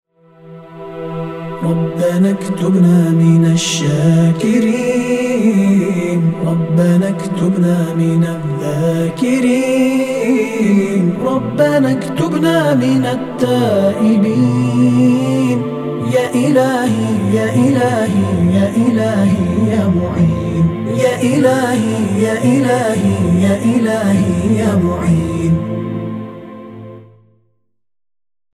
مبتهل، منشد و قاری ممتاز